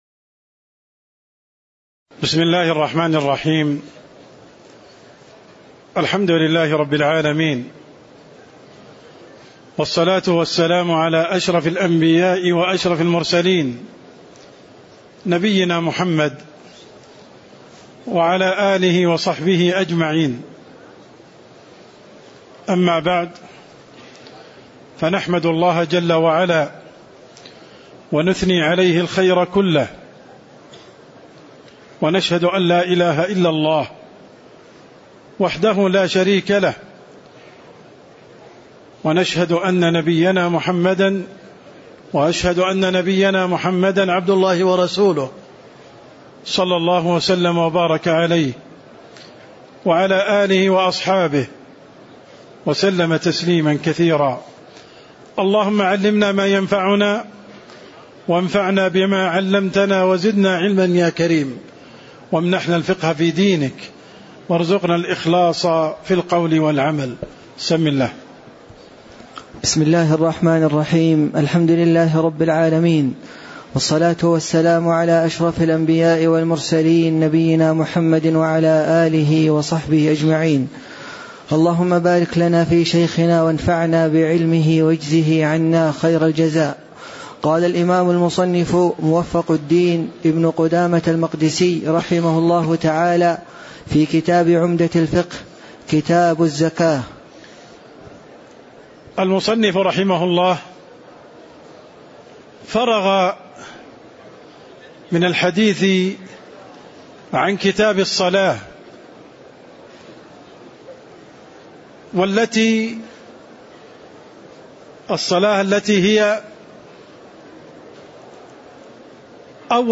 تاريخ النشر ٨ جمادى الآخرة ١٤٣٨ هـ المكان: المسجد النبوي الشيخ: عبدالرحمن السند عبدالرحمن السند المقدمة (01)